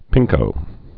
(pĭngkō)